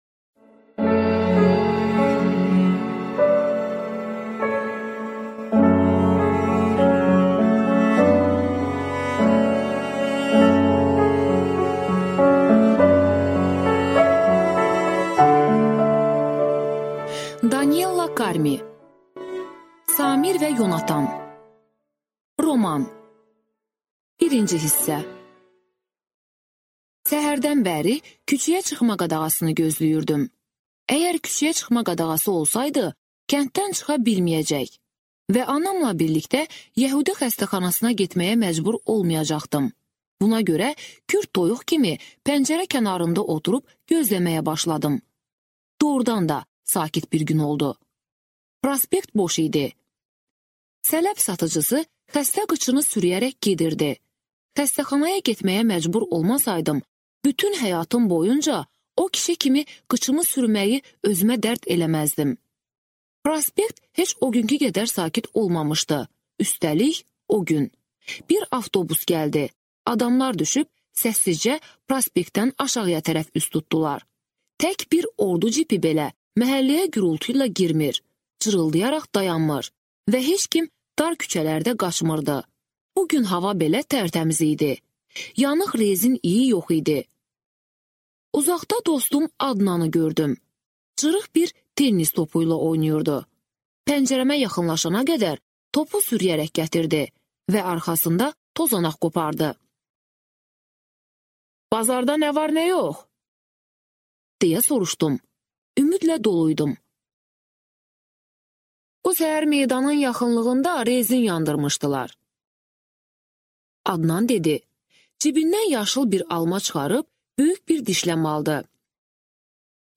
Аудиокнига Samir və Yonatan | Библиотека аудиокниг
Прослушать и бесплатно скачать фрагмент аудиокниги